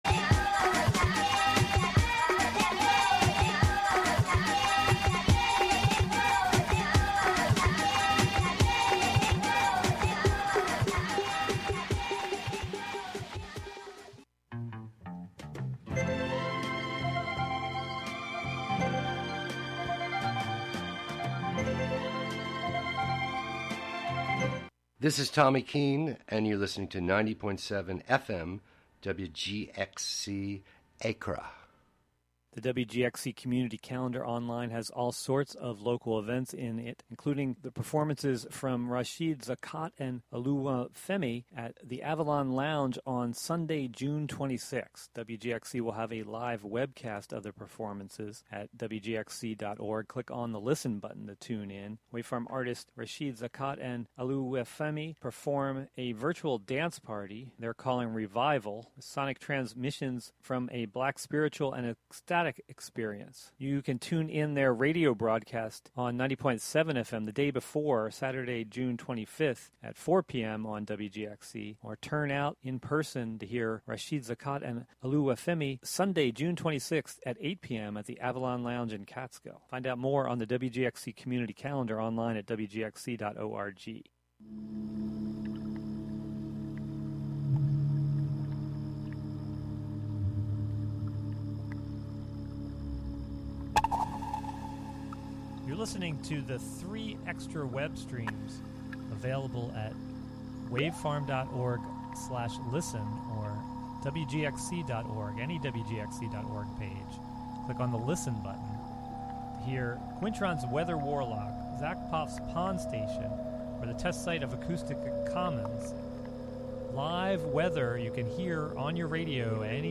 The show is broadcast live from Catskill, NY.